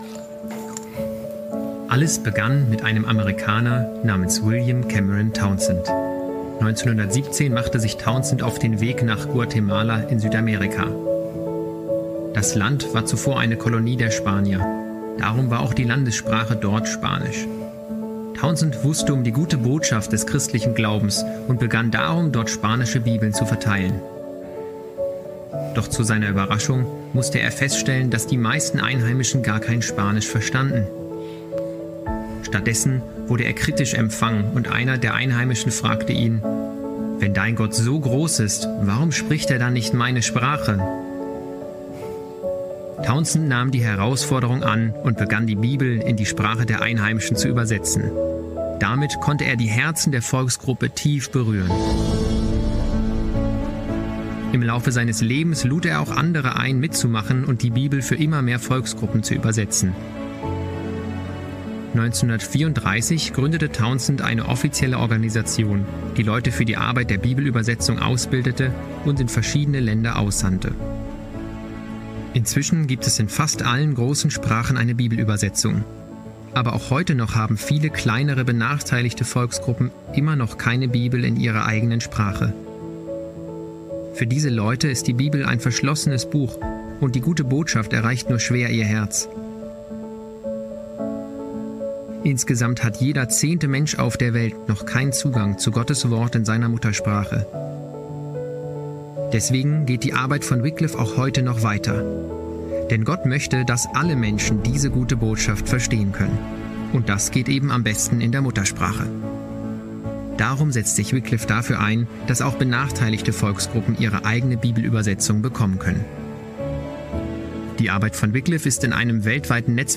Die Schönheit von Gottes Wort ~ Predigt-Podcast von unterwegs FeG Mönchengladbach Podcast